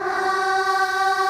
COED AAH.wav